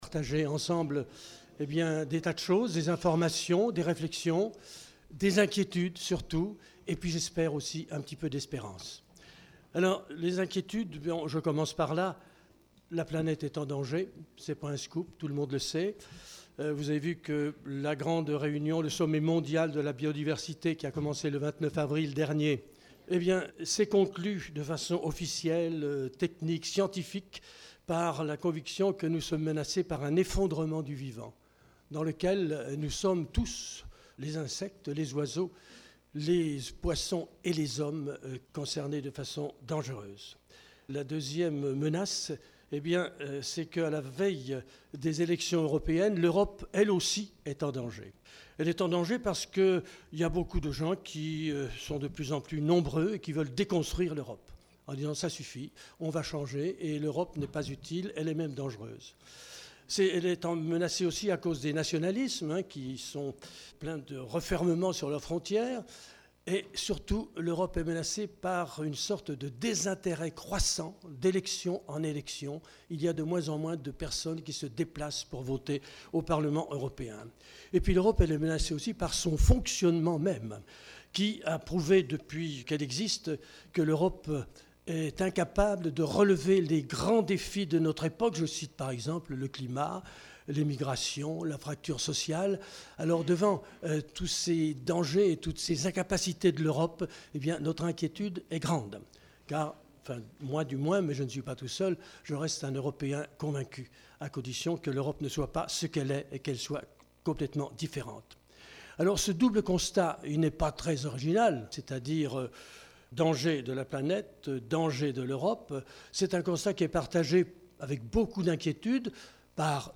Soirée 'Europe' du 6 mai au Centre diocésain
Le CCFD – Terre solidaire, le Service diocésain de l’écologie intégrale et du développement durable et le MRJC organisent deux soirées autour des enjeux écologiques et de la justice sociale des élections européennes. La soirée du 6 mai s’articulera autour de visionnages de documentaires suivis de débats qui permettront de se questionner ensemble sur les solutions à apporter dans les domaines de l’agriculture, de l’économie ou du social.